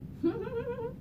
Betty Rubble Laugh